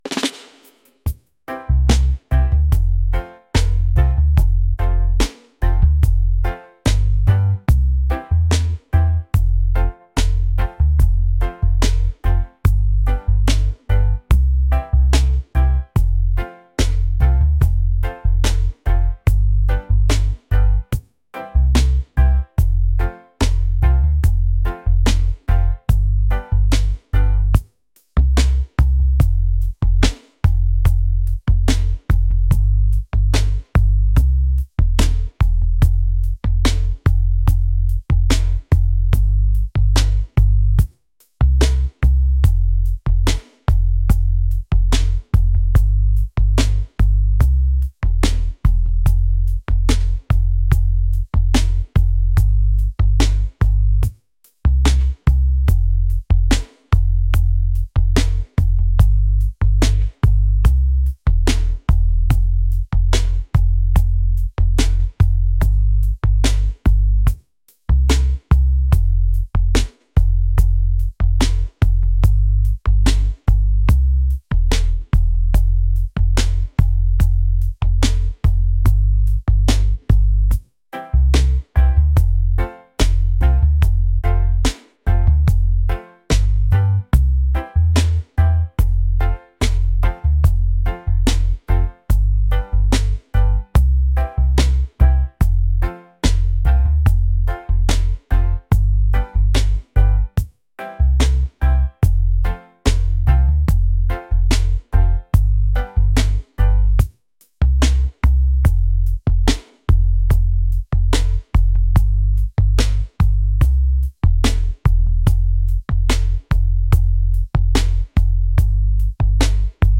reggae | laid-back | island | vibes